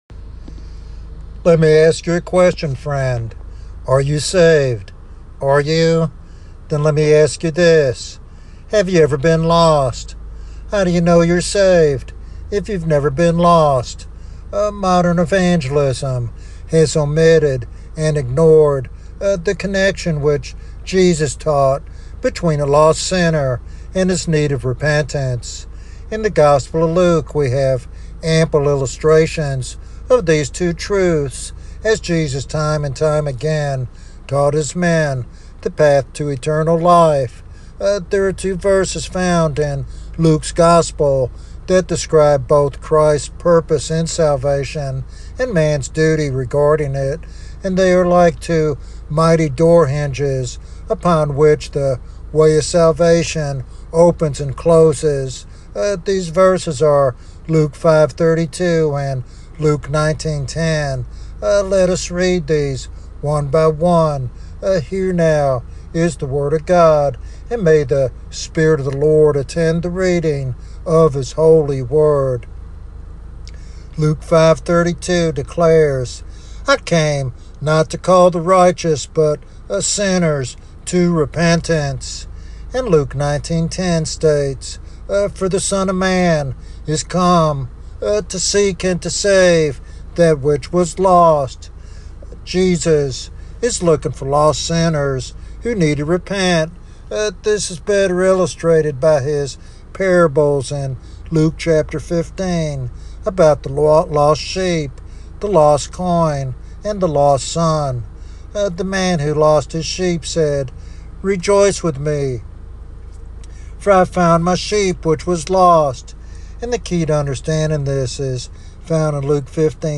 In this powerful evangelistic sermon
Sermon Outline